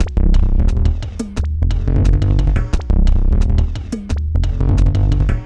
B + D LOOP 1 2.wav